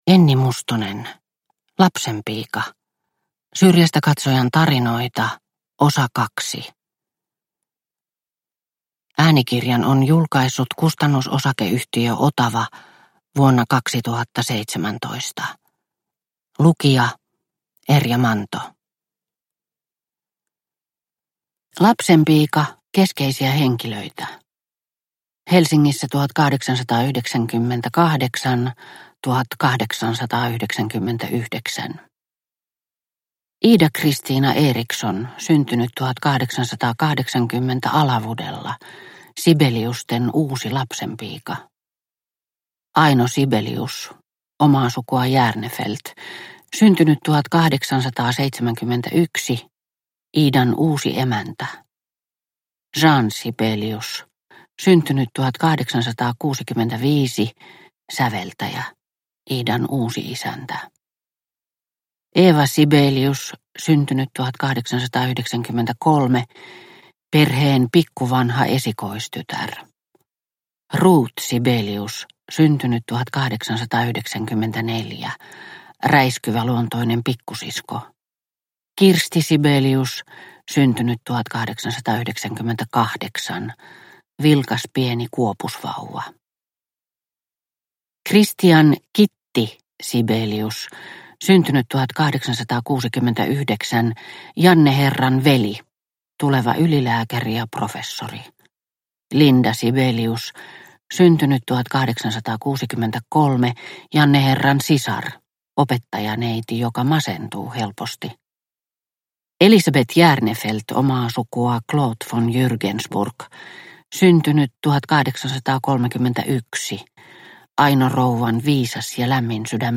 Lapsenpiika – Ljudbok – Laddas ner